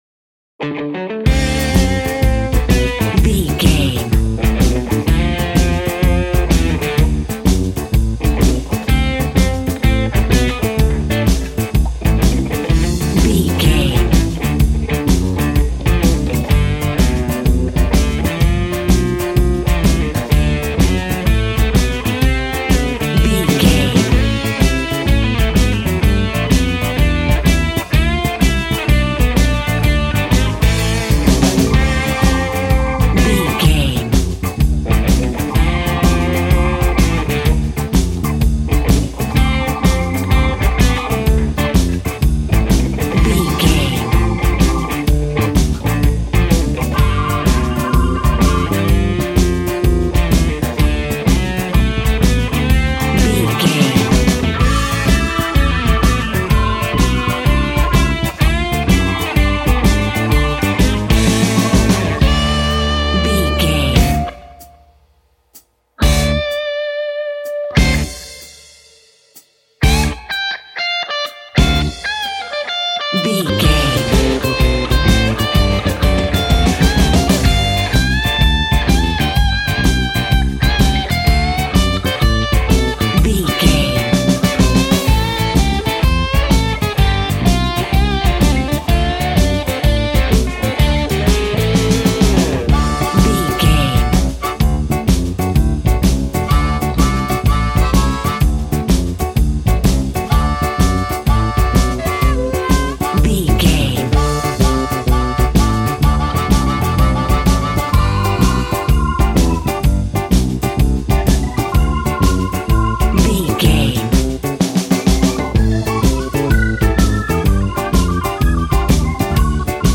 Ionian/Major
D
sad
mournful
bass guitar
electric guitar
electric organ
drums